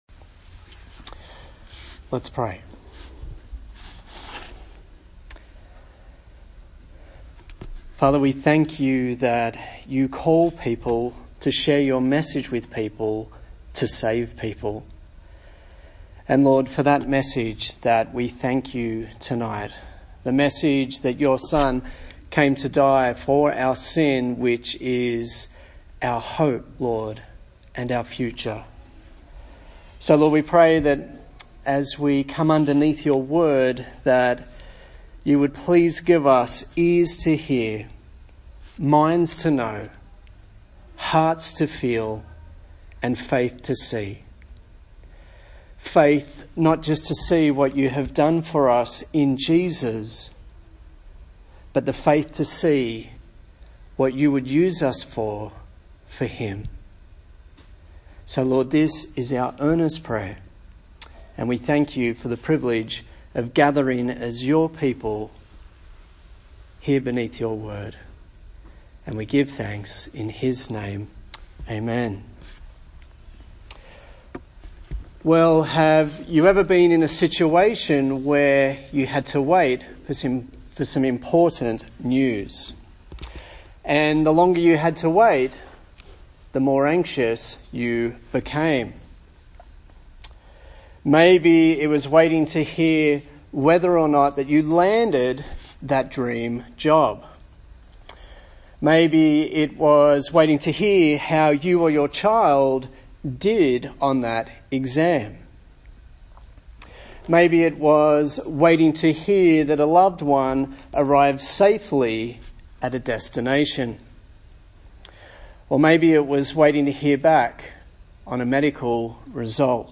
A sermon in the series on the book of 1 Thessalonians